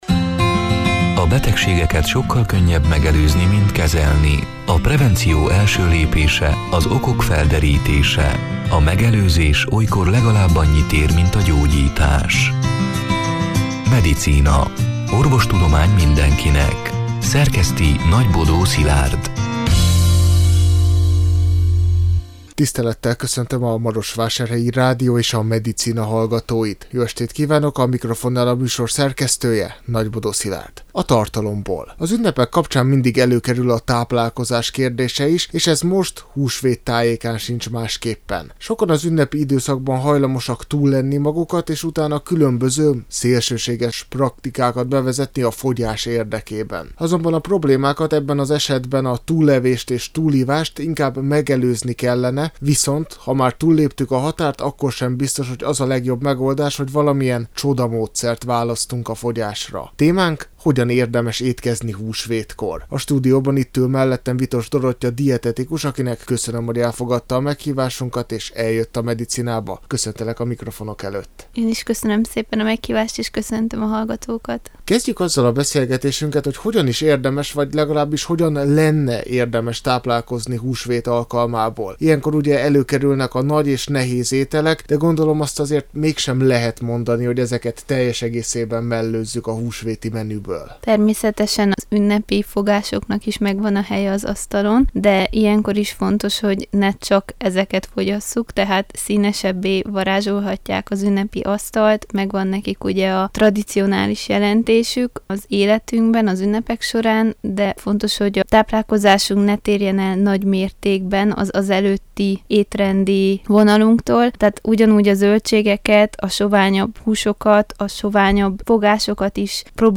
A Marosvásárhelyi Rádió Medicina (elhangzott: 2025. április 16-án, szerdán este nyolc órától) c. műsorának hanganyaga: